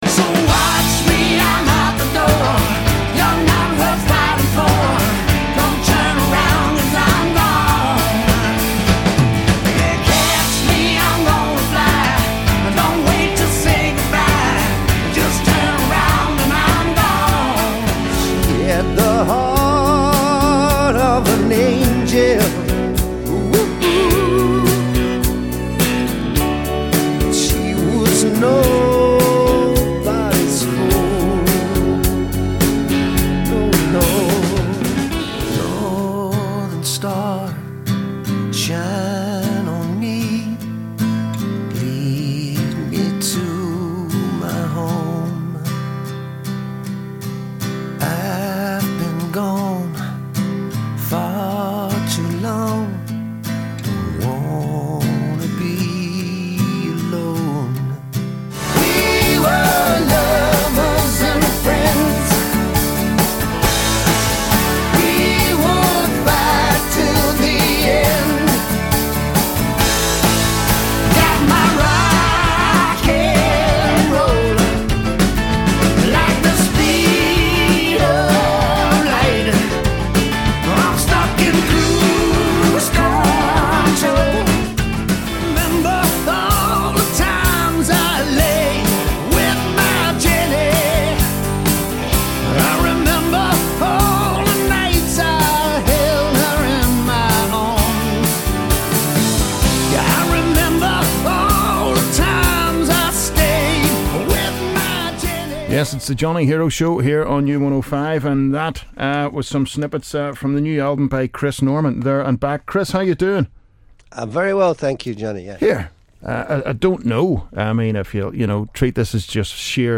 Chris Norman, lead singer of Smokie